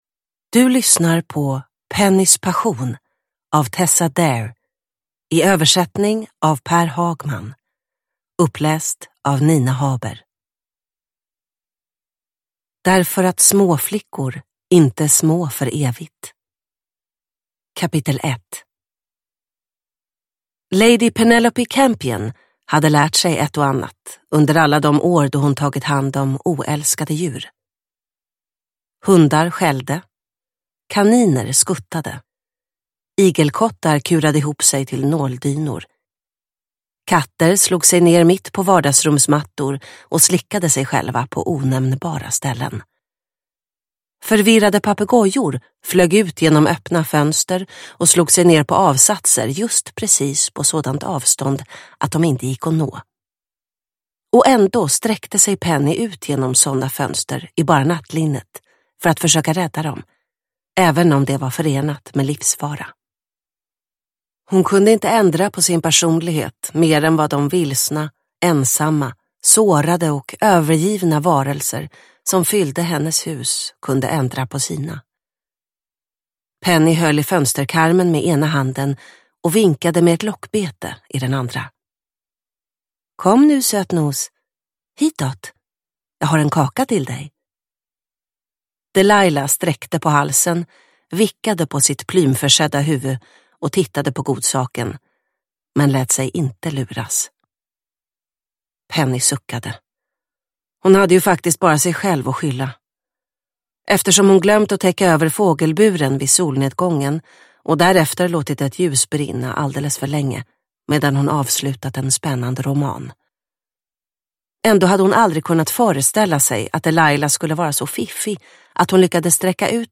Pennys passion – Ljudbok – Laddas ner